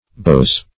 Beaus (b[=o]z).